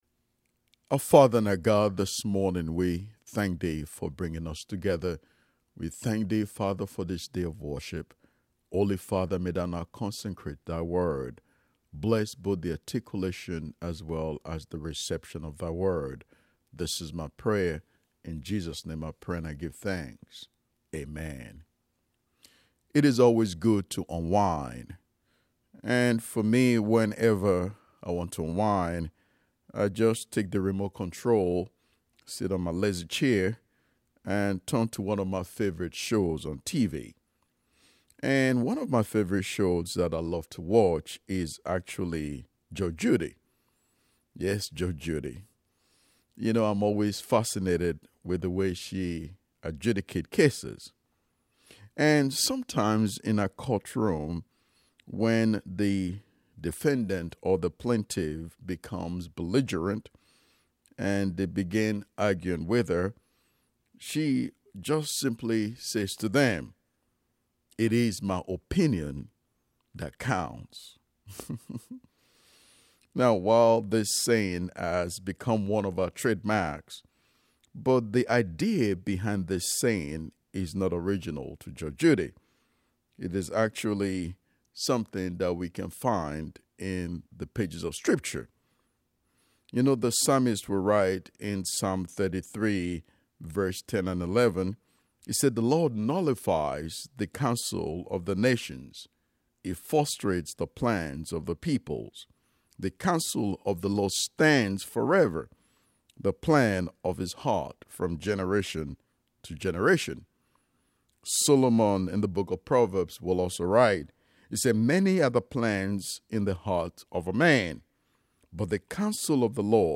10:30 AM Service
10:30 AM Service God Has the Final Say Click to listen to the sermon. https